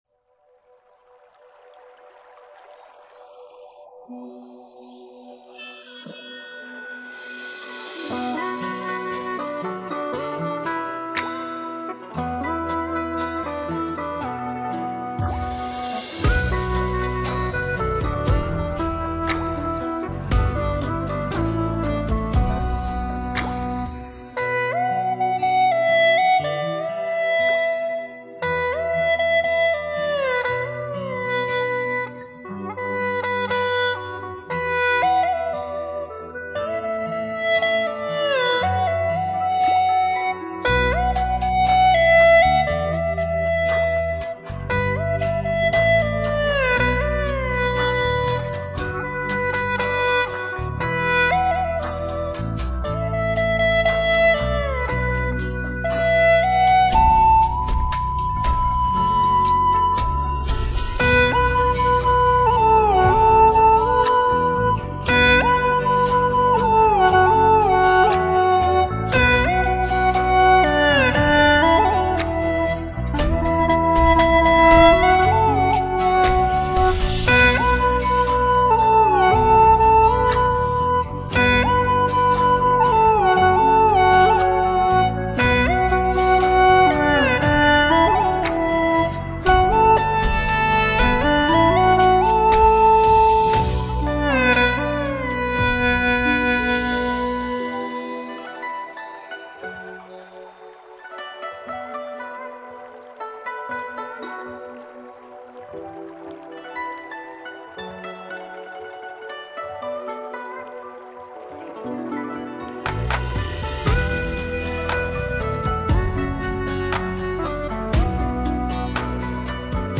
调式 : D 曲类 : 流行